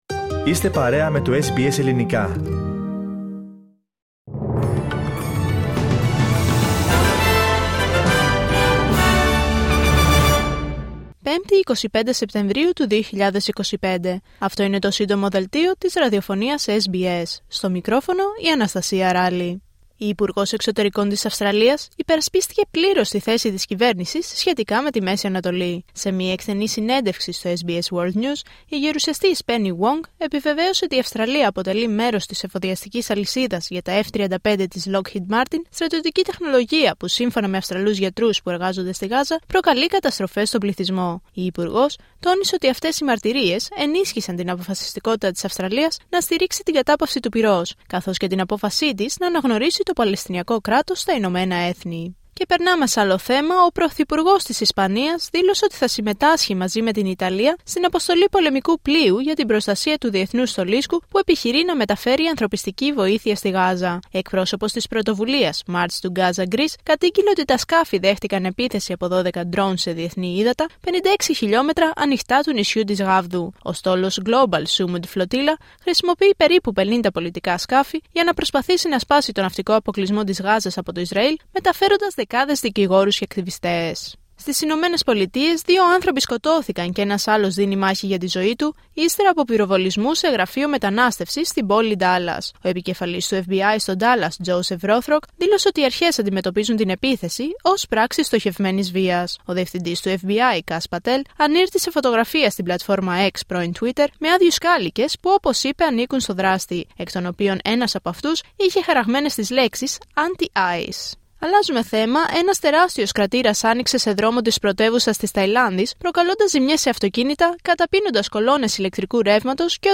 H επικαιρότητα έως αυτή την ώρα στην Αυστραλία, την Ελλάδα, την Κύπρο και τον κόσμο στο Σύντομο Δελτίο Ειδήσεων της Πέμπτης 25 Σεπτεμβρίου 2025.